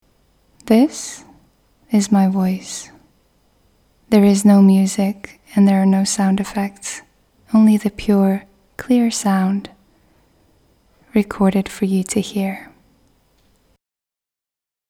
Adult (30-50)
Female Voice Over Talent
0820Introduction.Clear.Warm.mp3